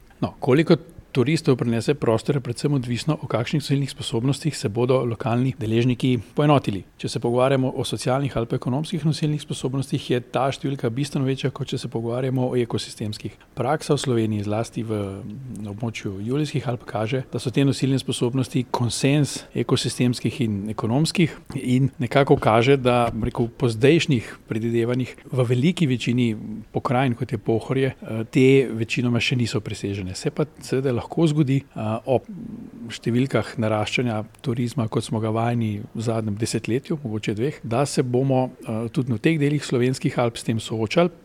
V Mislinji je včeraj potekala okrogla miza o tem, kako se izogniti pastem množičnega turizma na Pohorju.